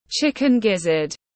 Mề gà tiếng anh gọi là chicken gizzard, phiên âm tiếng anh đọc là /ˈʧɪkɪn ˈgɪzəd/
Chicken gizzard /ˈʧɪkɪn ˈgɪzəd/